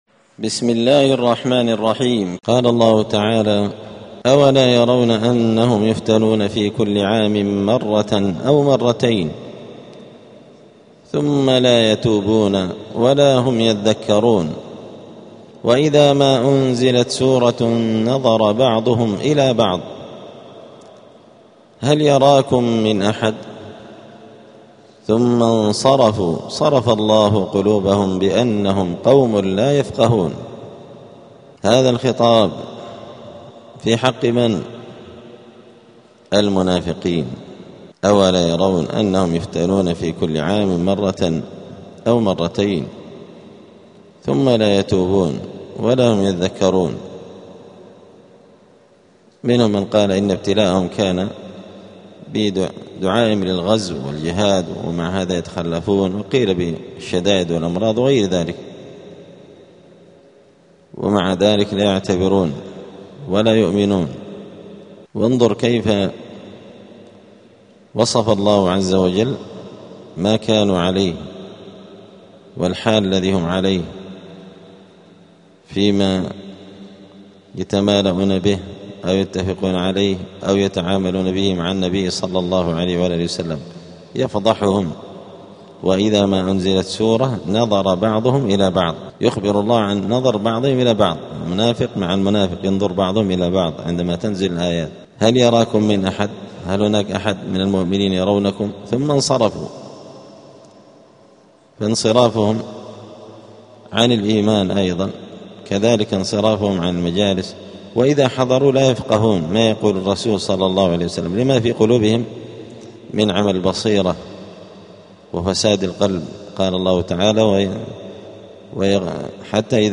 📌الدروس اليومية
دار الحديث السلفية بمسجد الفرقان قشن المهرة اليمن